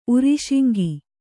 ♪ uriśiŋgi